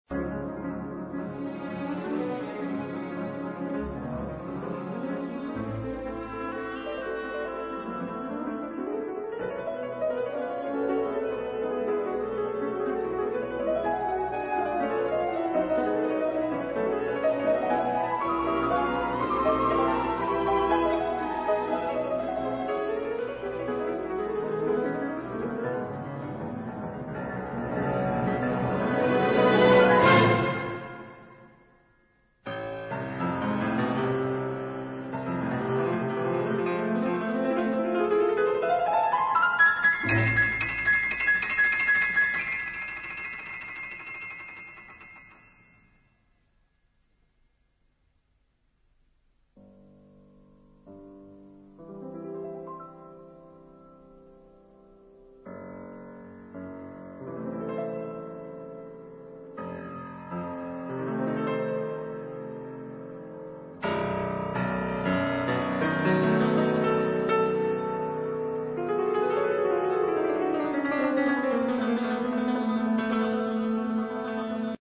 سپس ارکستر، زمینه را برای تکنوازی پیانو و به نمایش گذاشتن مهارت هایش فراهم می سازد.